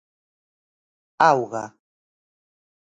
Pronunciado como (IPA)
/ˈaw.ɣɐ/